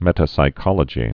(mĕtə-sī-kŏlə-jē)